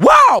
Rhythm Machine Sound "RX5"